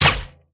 bow_shot_a.ogg